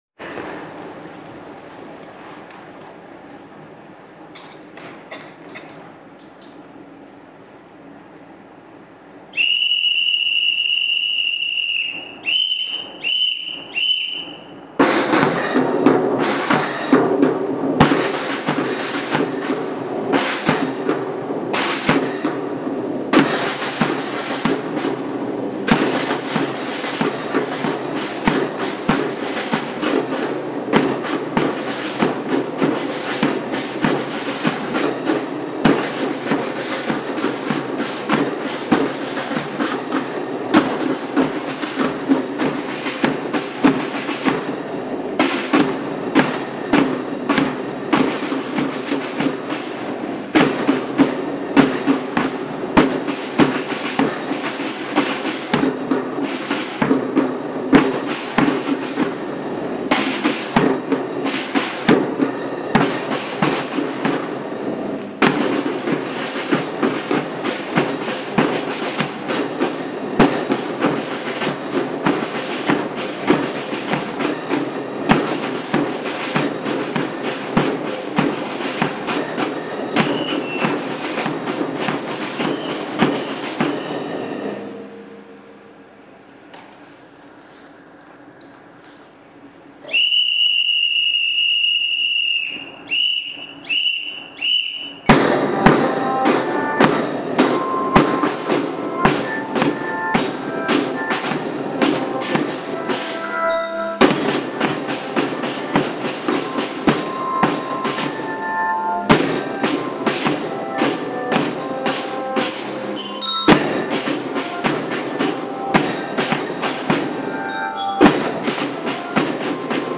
2月19日(火)、鼓笛引継会を行いました。
まずは6年生の演奏です。
6年生の演奏.MP3　（ＩＣレコーダーでの演奏ですが、クリックしてどうぞお聴きください。）
とても落ち着いた演奏でした。